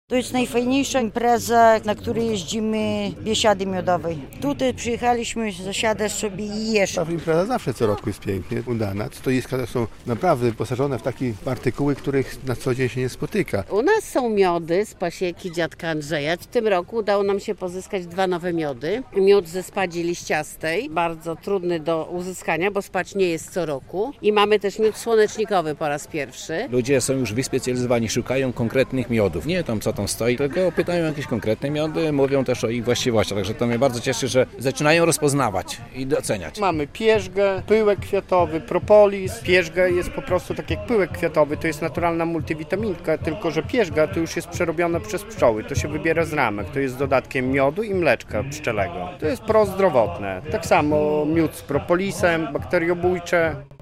Biesiada Miodowa w Kurowie - relacja